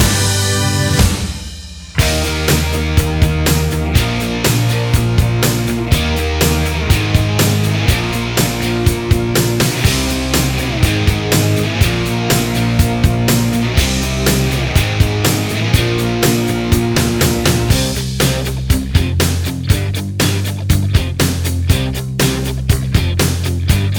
Minus Lead Guitars Pop (1980s) 3:55 Buy £1.50